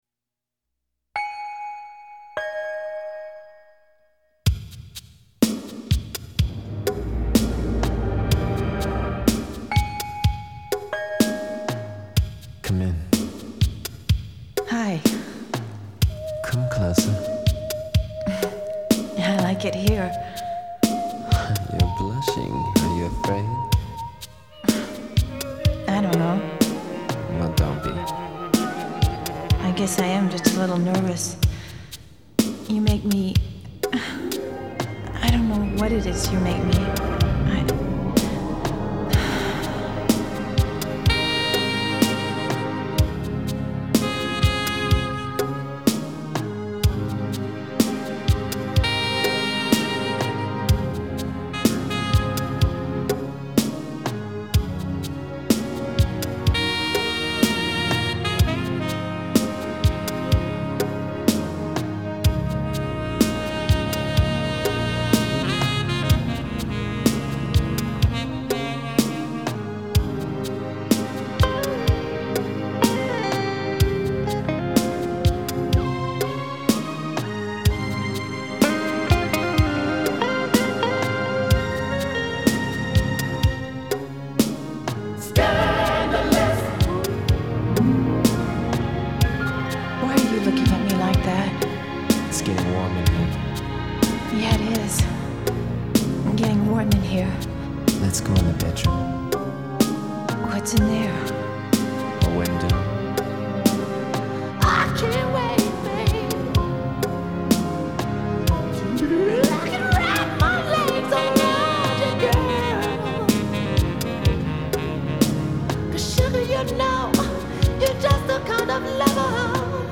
smooth jazz remix
breezy
sax